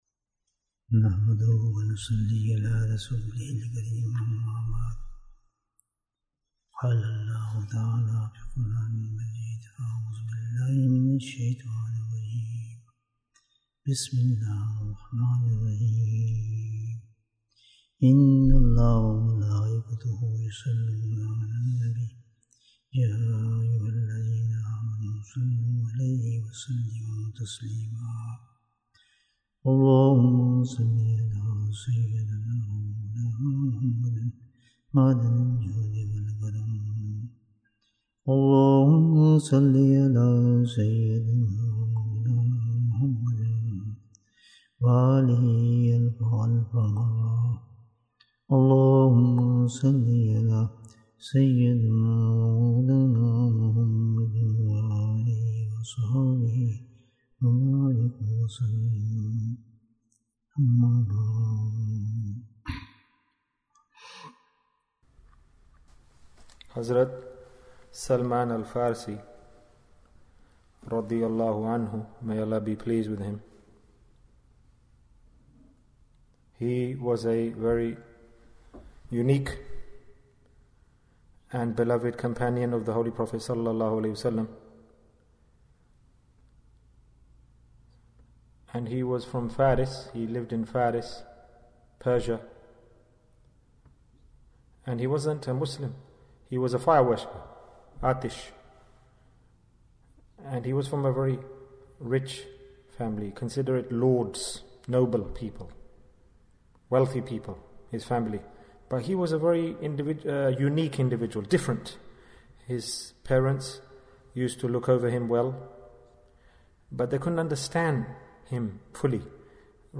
Beautiful Dua Bayan, 44 minutes29th December, 2022